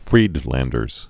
(frēdlĕndərz)